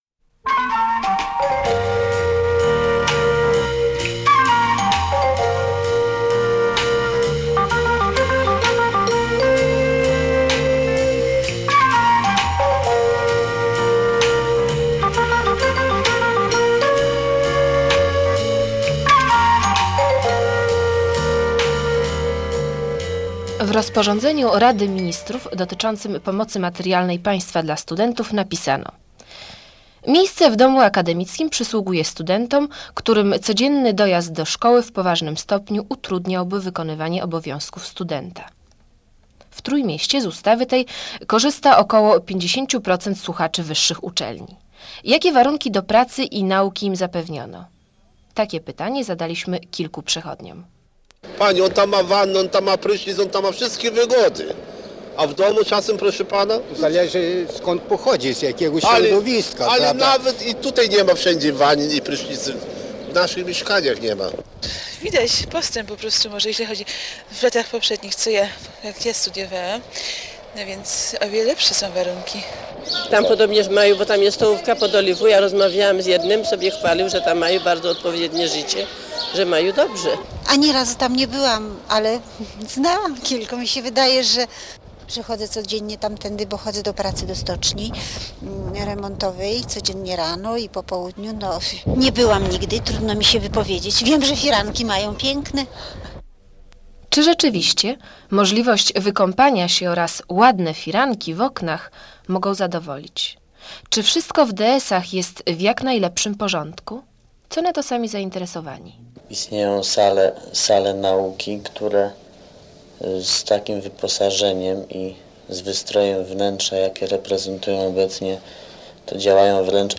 O mankamentach zamieszkiwania w akademikach: wypowiedzi studentów PG